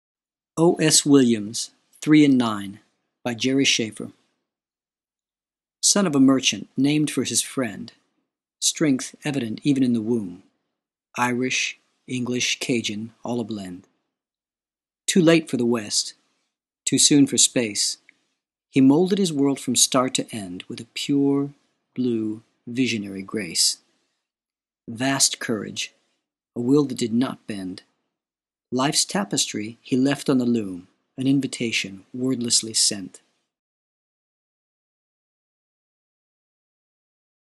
To listen to a reading of the poem, click on the play arrow: